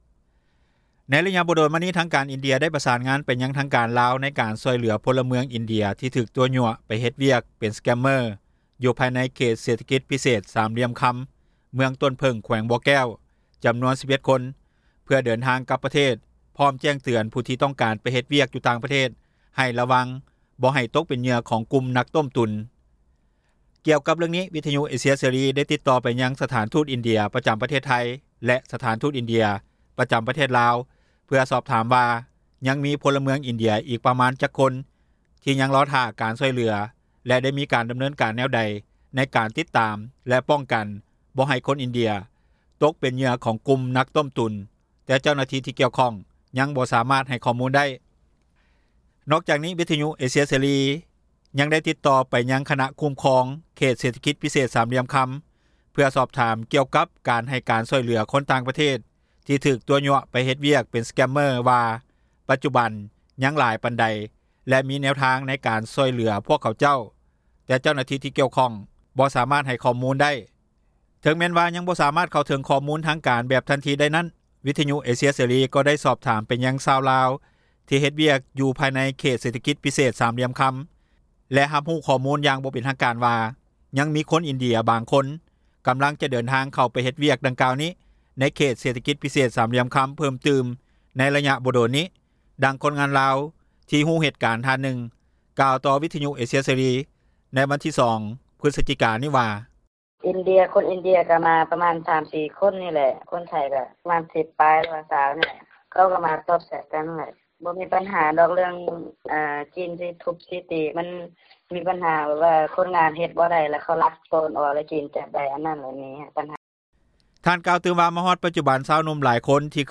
ດັ່ງຄົນງານລາວ ທີ່ຮູ້ເຫດການທ່ານນຶ່ງ ກ່າວຕໍ່ວິທຍຸ ເອເຊັຽເສຣີ ໃນວັນທີ 02 ພຶສຈິກາ 2022 ນີ້ວ່າ:
ດັ່ງນັກກົດໝາຽ ທີ່ຕິດຕາມຂ່າວສານ ກ່ຽວກັບຂະບວນການ ຕົວະຕົ້ມ ໃຫ້ມາເຮັດວຽກ ເປັນສແກມເມີຣ໌ ຢູ່ປະເທດລາວ ທ່ານນຶ່ງກ່າວວ່າ: